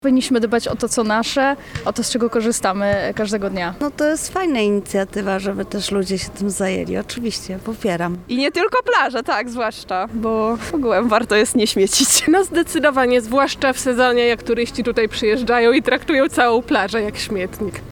Nasz reporter pytał spacerujących po gdyńskiej plaży czy takie akcje są potrzebne i czy plaże są zaśmiecone?